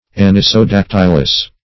Search Result for " anisodactylous" : The Collaborative International Dictionary of English v.0.48: Anisodactylous \An`i*so*dac"ty*lous\, a. (Zool.)
anisodactylous.mp3